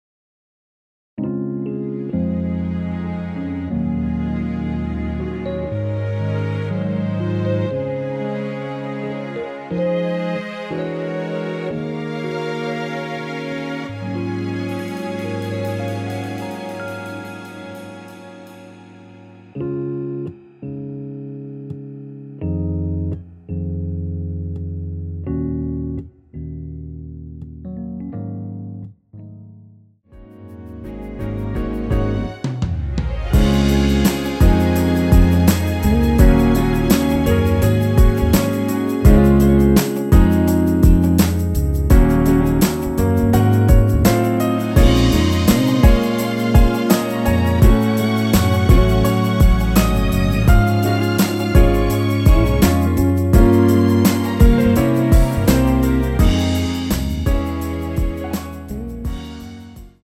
원키에서(-1)내린 MR입니다.
Db
앞부분30초, 뒷부분30초씩 편집해서 올려 드리고 있습니다.